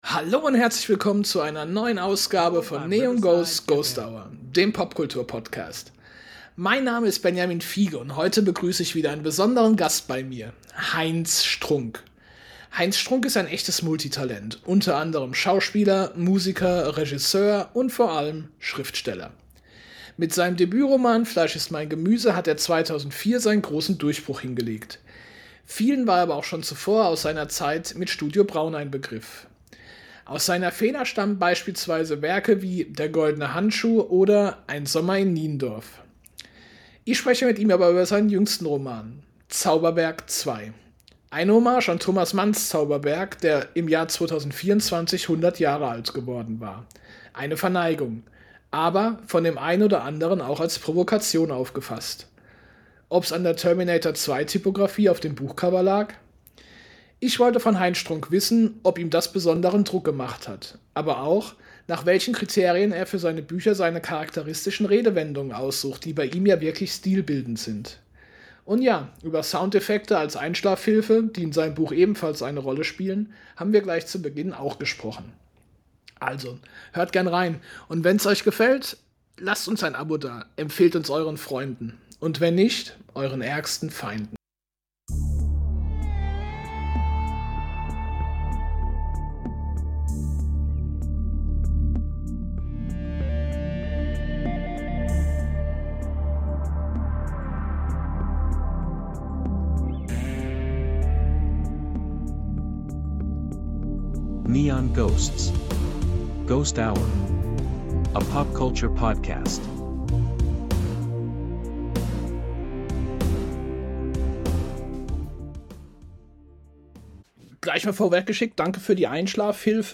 Im Interview: Heinz Strunk - Terminator meets Thomas Mann ~ NEON GHOSTS: GHOST HOUR Podcast